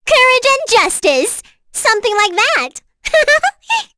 Cecilia-Vox_Victory.wav